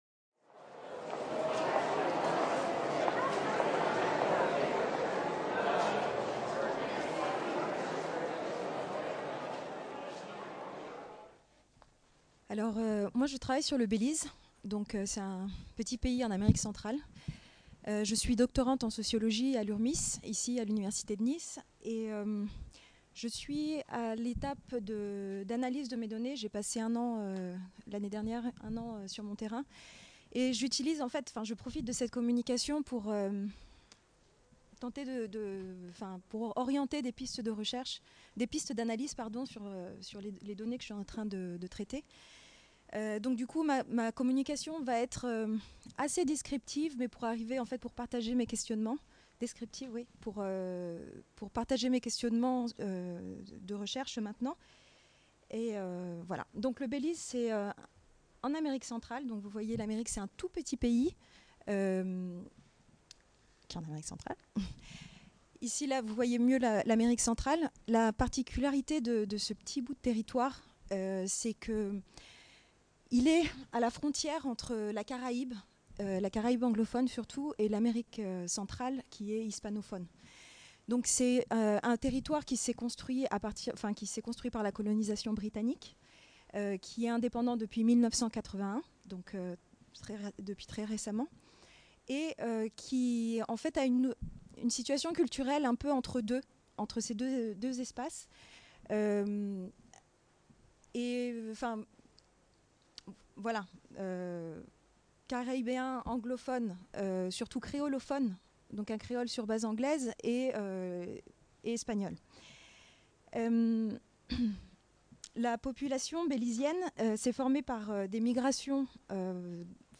Journée d’études organisée par l’Urmis, Université Côte d’Azur, IRD, le jeudi 13 décembre 2018 à Nice.